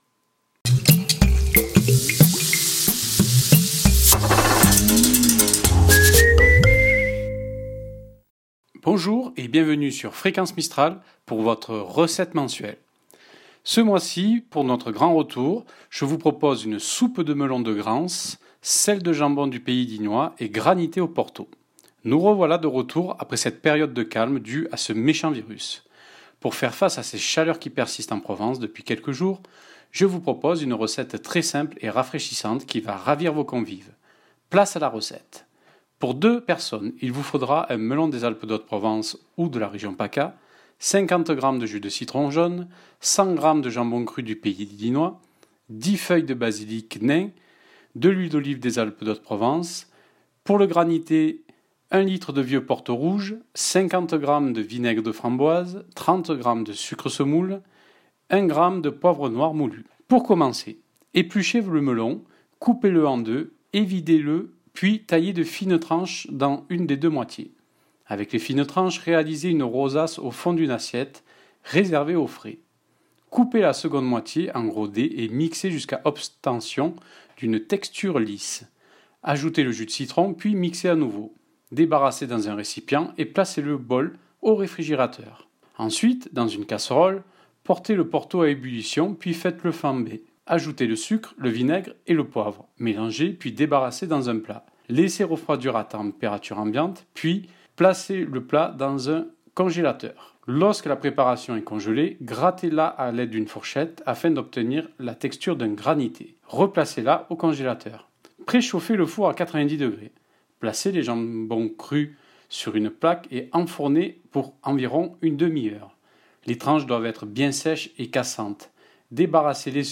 Tous les mois (premier lundi du mois) , retrouvez une recette diffusée sur Fréquence Mistral Digne dans la matinale à partir de 9h15, sur le quotidien et internet Haute-Provence Info et dans votre magasin ETAL DES 3 Vallees et Dignamik sous forme de fiches recettes. Des recettes comme d'habitude élaborées avec des produits des Alpes de Haute-Provence et de notre région PACA .